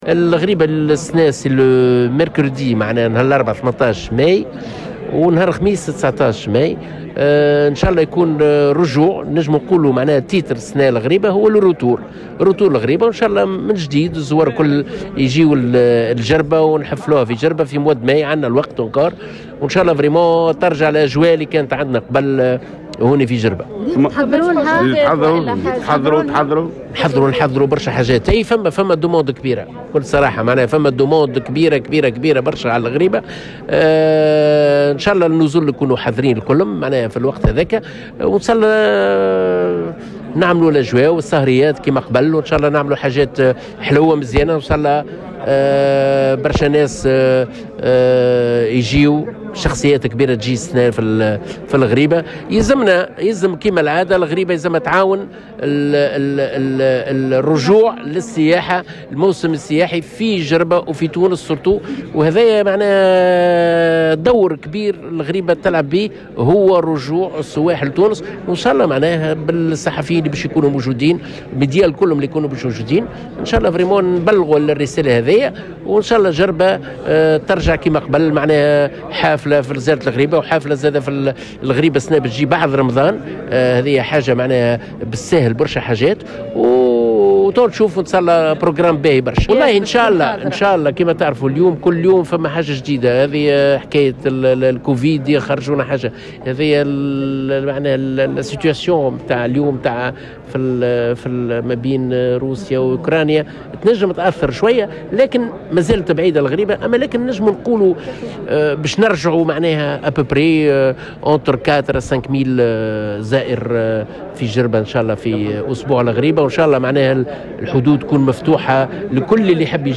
أكد منظم زيارة الغريبة ووزير السياحة الأسبق، روني الطربلسي، في تصريح لمراسلة الجوهرة اف ام، اليوم الثلاثاء، عودة الزيارة السنوية لمعبد الغريبة بجربة، والتي ستنظم هذه السنة يومي الاربعاء 18 والخميس 19 ماي 2022.